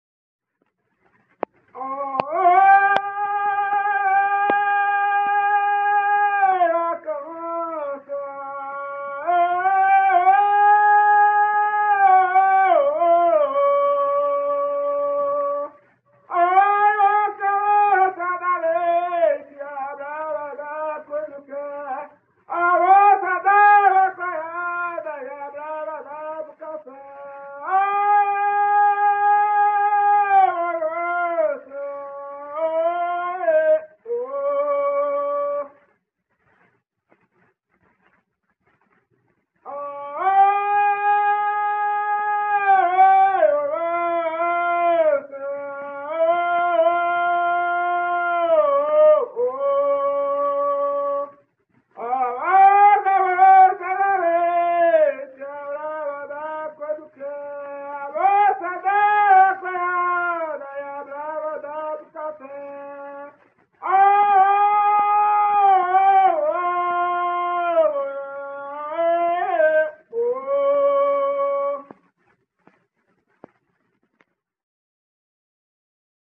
Aboio